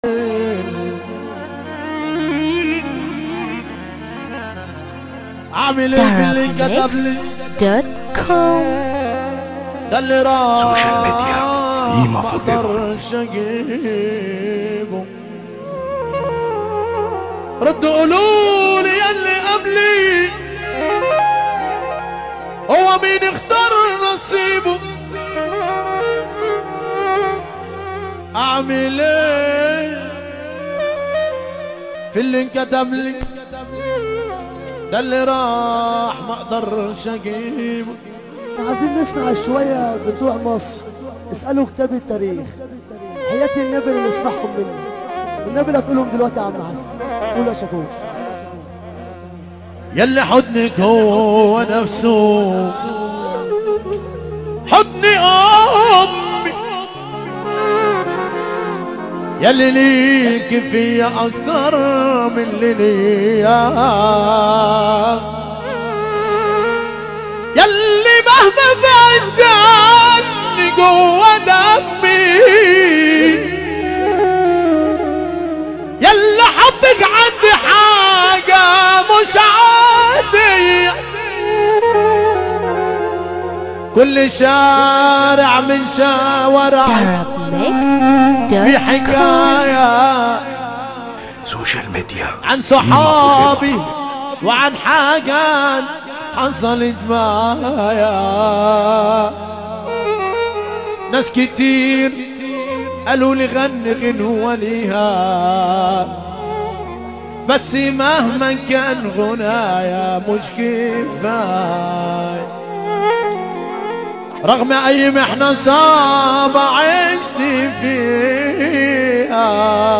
موال
حزينة موت